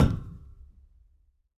tbd-station-14/Resources/Audio/Effects/Footsteps/hull5.ogg